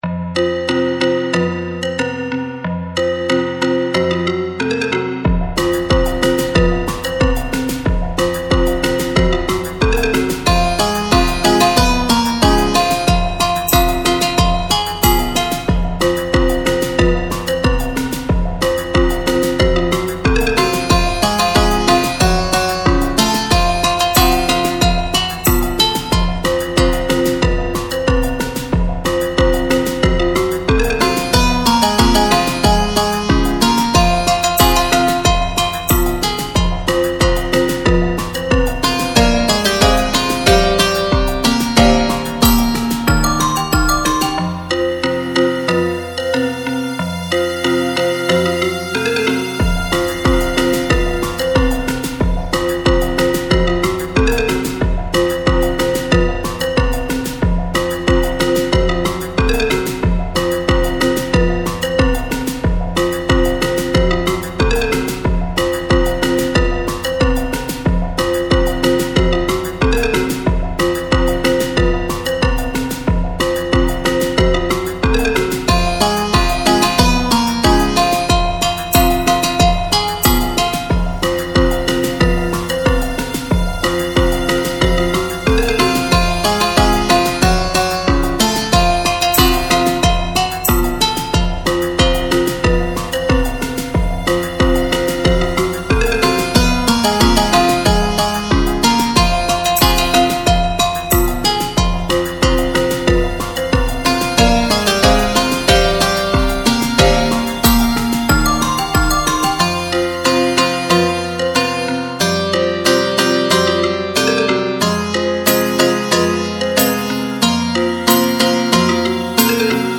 2v. e pf